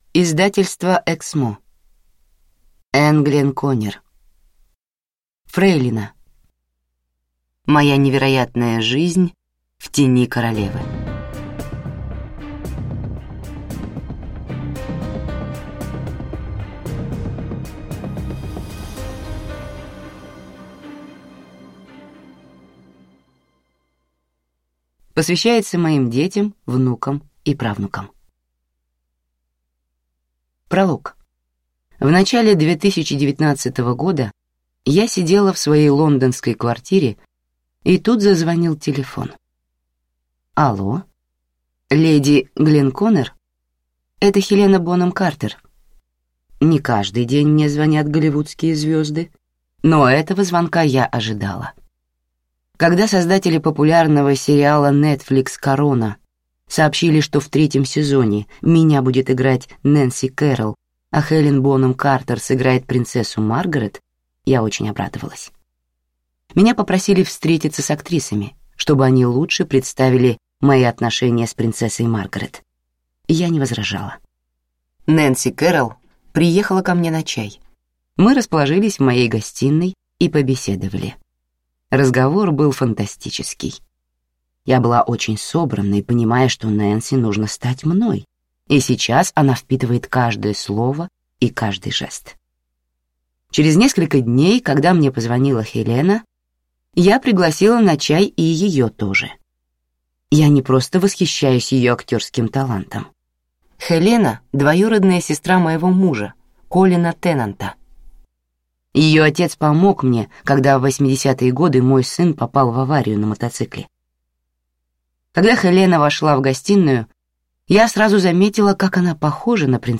Аудиокнига Фрейлина. Моя невероятная жизнь в тени Королевы | Библиотека аудиокниг